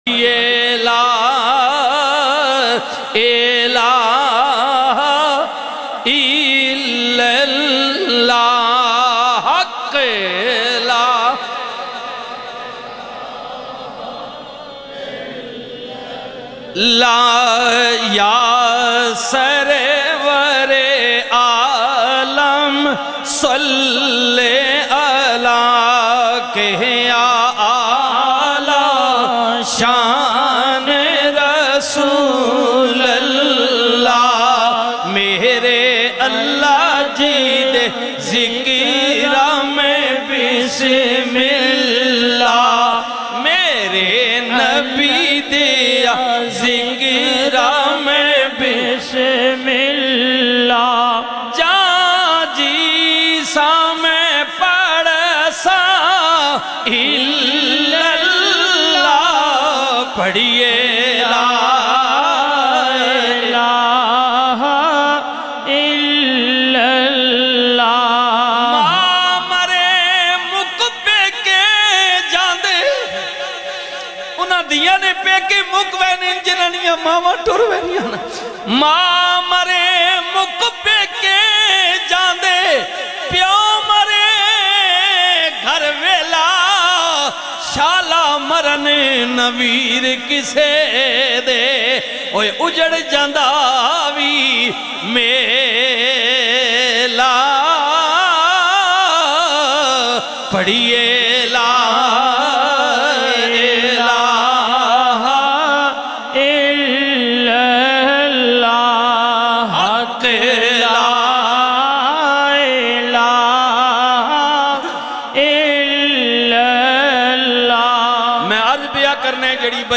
Wird Kalma Sharif Beautifull Voice
zikar-kalma-sharif.mp3